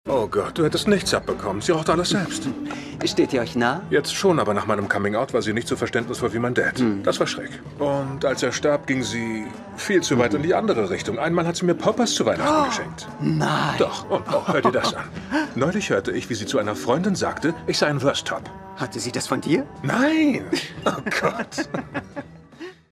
Easy Jet werbung